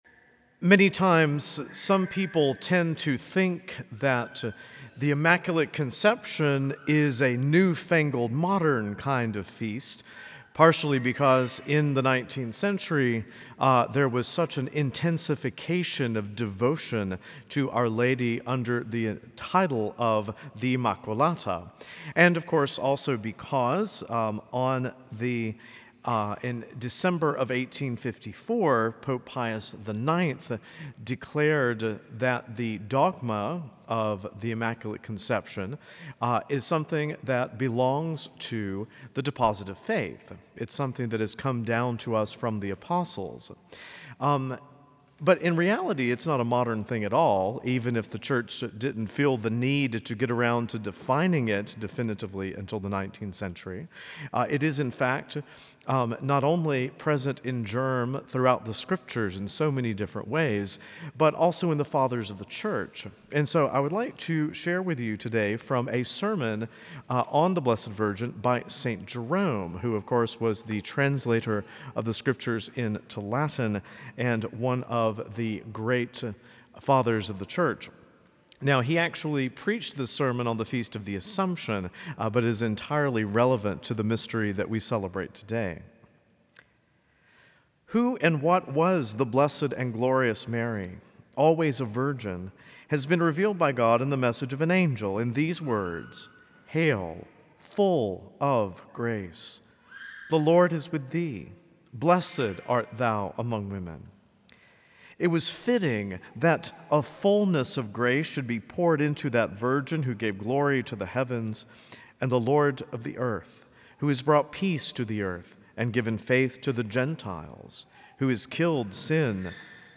A homily from the series "Homilies."
From Series: "Homilies"